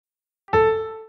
Нота Ля в музыке